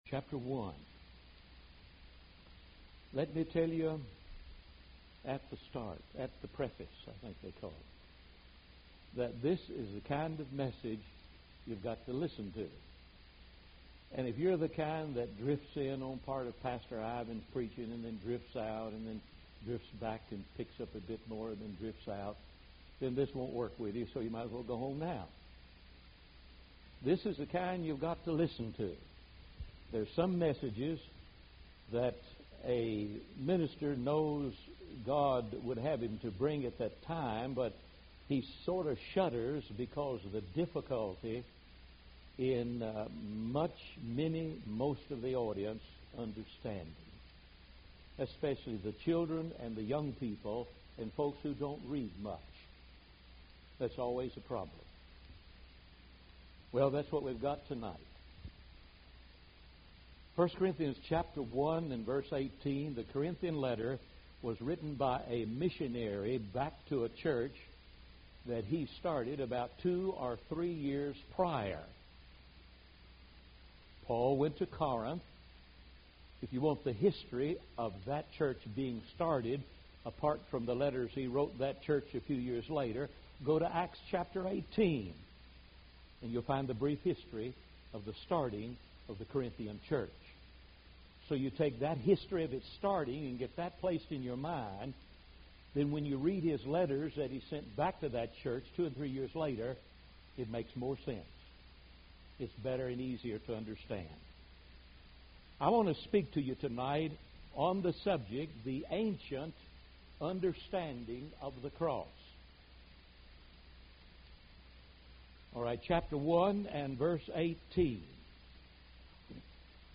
audio & video sermons